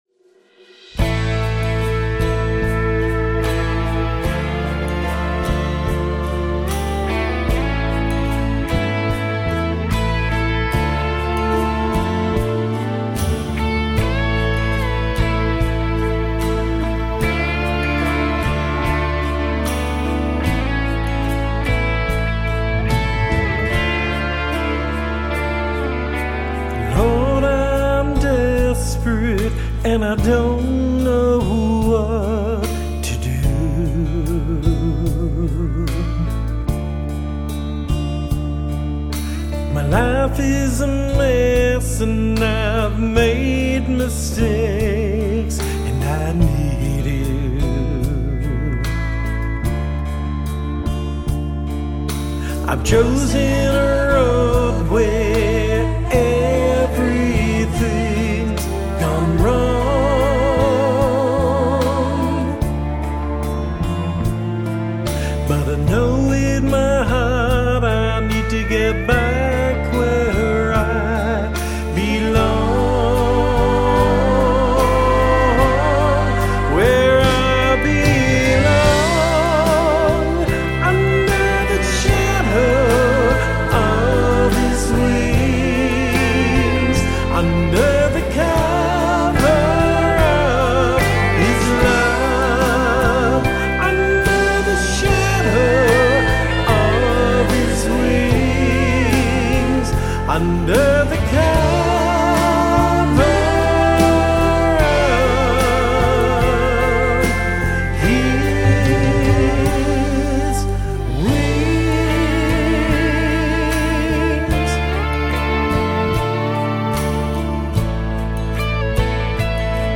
A Christian vocal trio